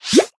Minecraft Version Minecraft Version 25w18a Latest Release | Latest Snapshot 25w18a / assets / minecraft / sounds / mob / pufferfish / blow_up2.ogg Compare With Compare With Latest Release | Latest Snapshot
blow_up2.ogg